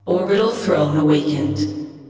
I am male, but I replaced them with AI voice tones to achieve female voice conversion.